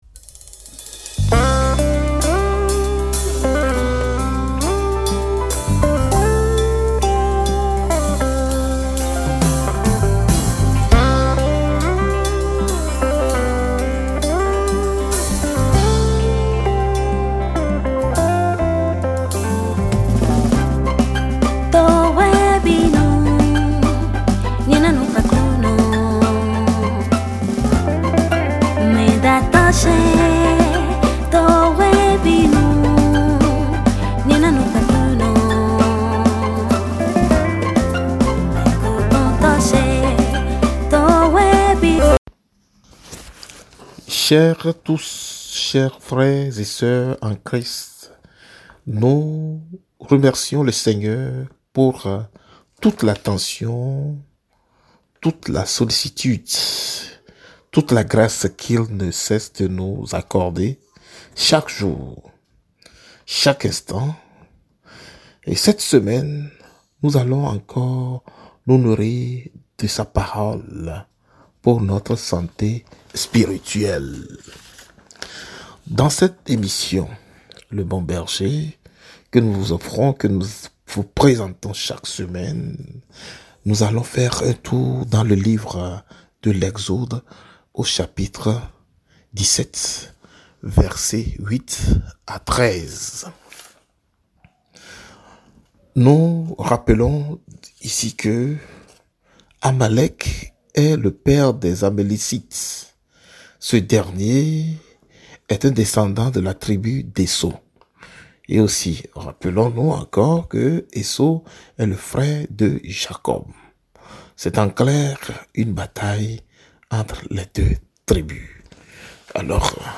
Une émission animée